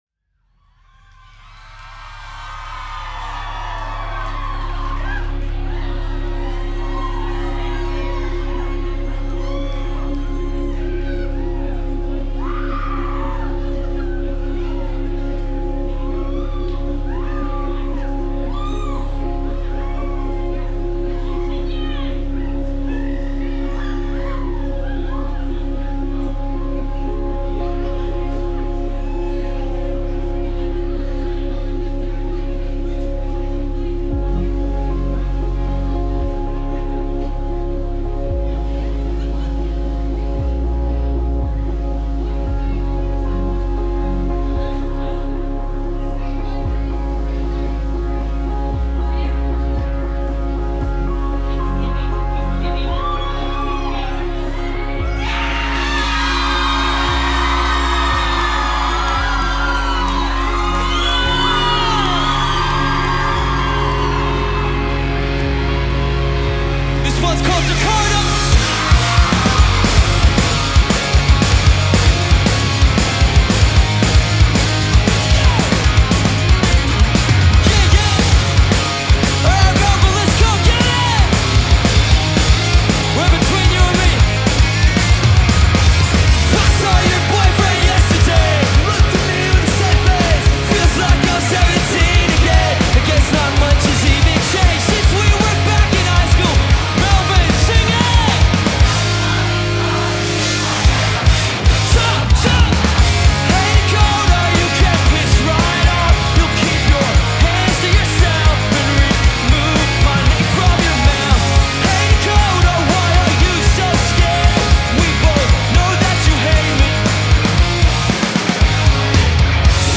recorded May 17, 2024 at 170 Russell, Melbourne
guitar
drums
bass guitar